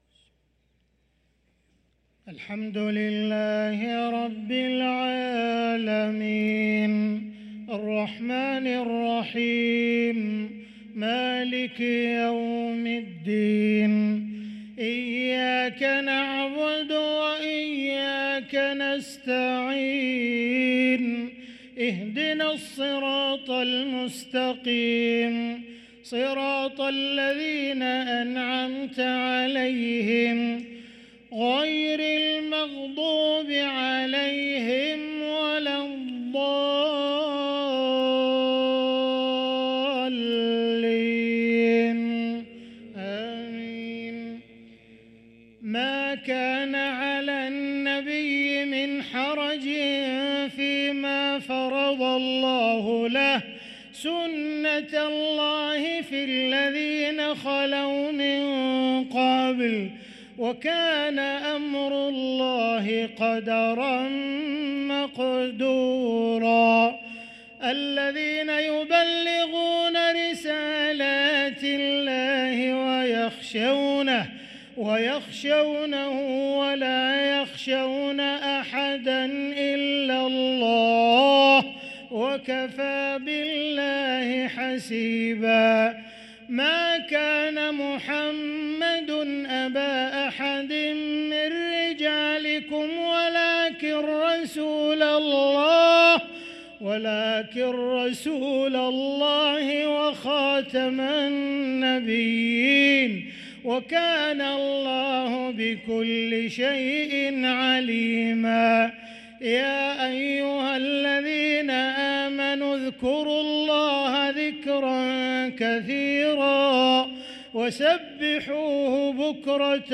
صلاة العشاء للقارئ عبدالرحمن السديس 22 صفر 1445 هـ
تِلَاوَات الْحَرَمَيْن .